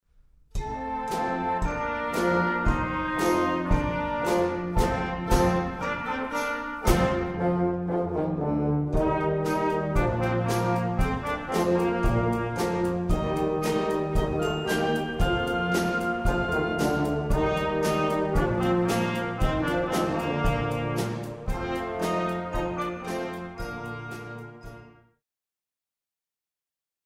FOR BEGINNERS